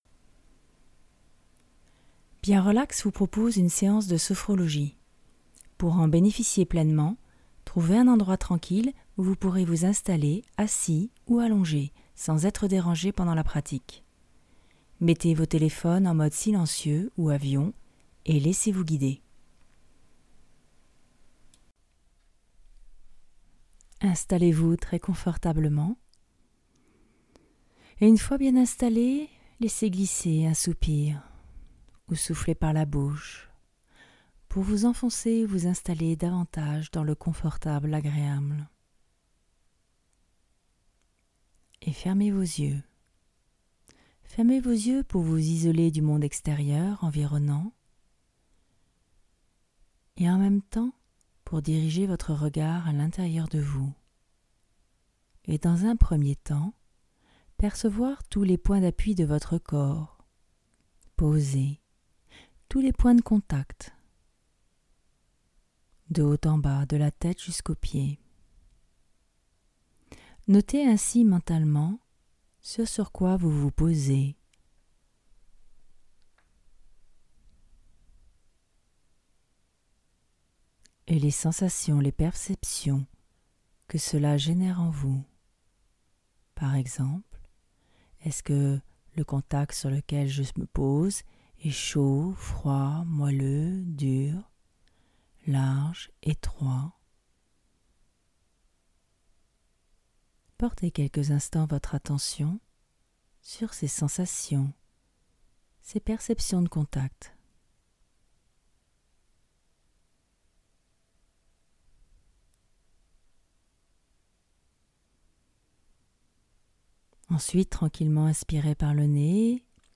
Genre : Sophrologie.